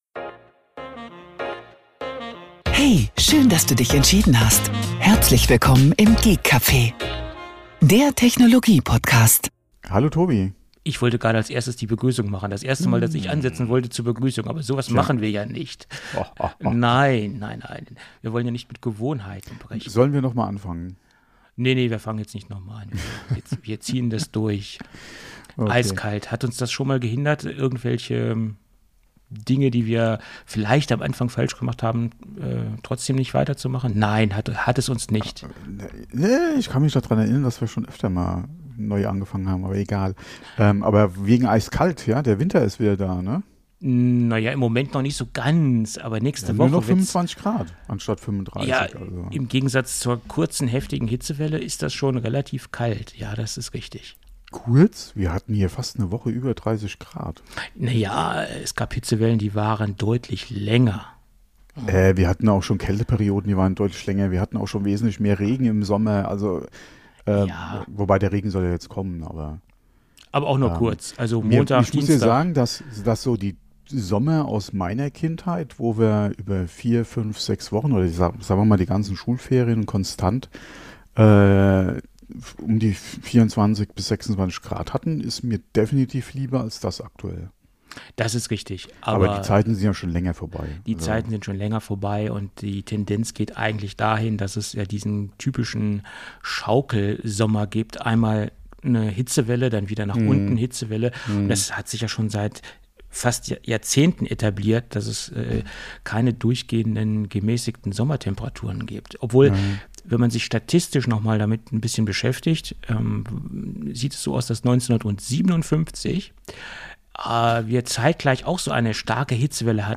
Über 7 Jahre veröffentlichten wir so insgesamt über 320 Folgen mit Gadget Reviews, App-Tipps, den neusten News aus der Apfelwelt und etliche Sonderfolgen. Die lockere Atmosphäre während der Aufnahme ist dem Konzept – oder eben dem Fehlen desselbigen zu verdanken.